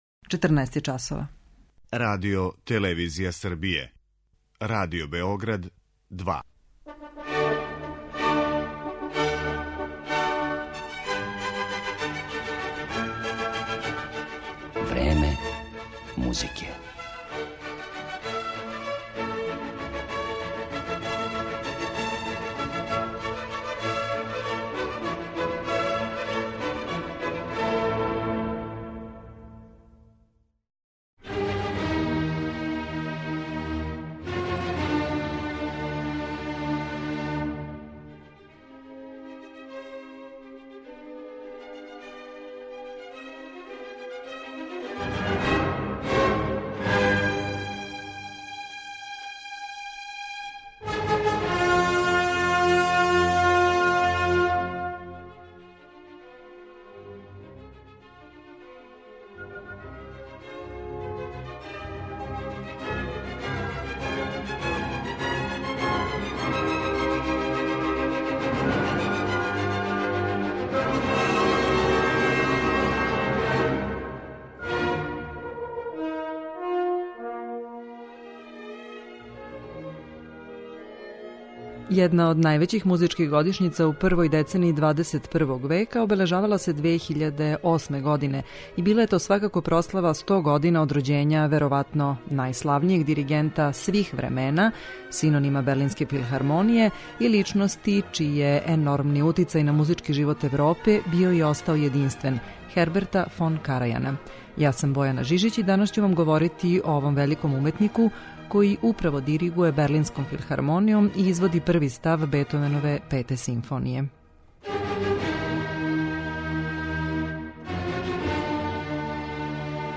Поред изврсних уметникових тумачења пре свега немачке музике, моћи ћете да чујете и интервју са Карајаном објављен у британском музичком часопису "Грамофон" 2008. године када је обележавано 100 година од рођења овог великог диригента.